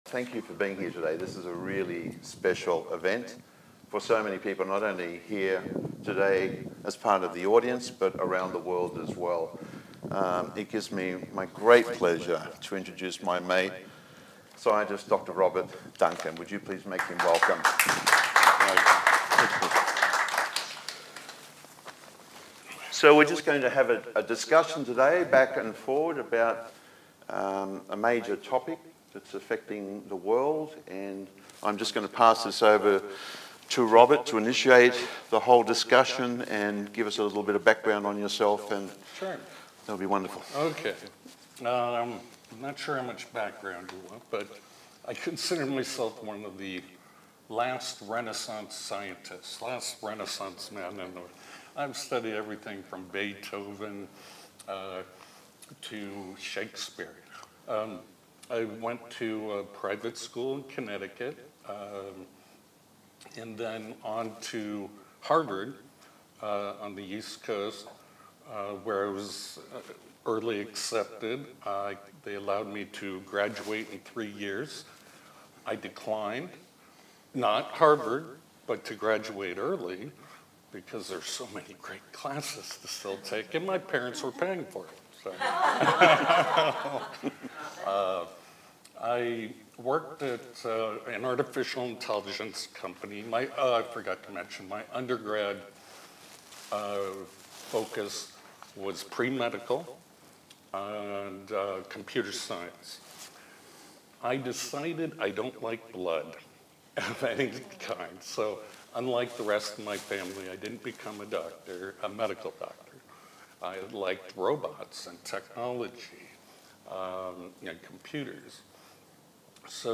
and A.I. The presentation took place in Sydney, Australia on January 28, 2023. The future of children and generations and how they will be tortured through these technologies was discussed.